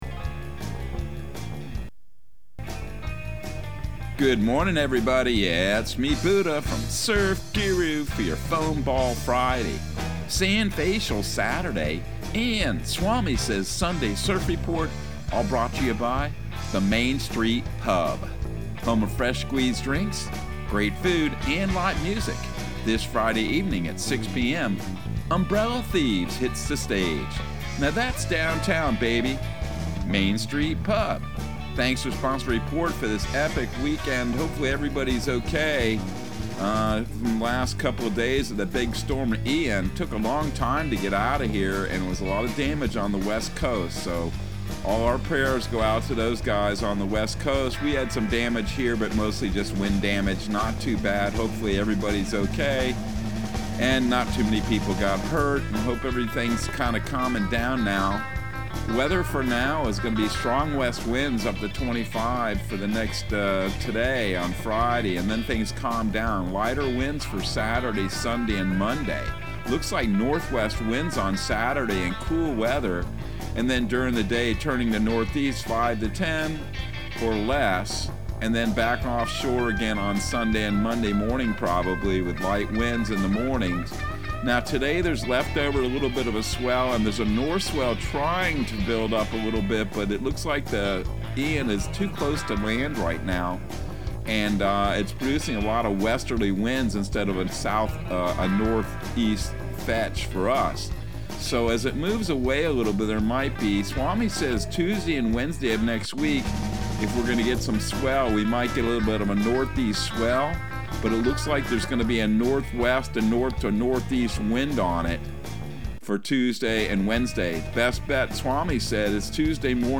Surf Guru Surf Report and Forecast 09/30/2022 Audio surf report and surf forecast on September 30 for Central Florida and the Southeast.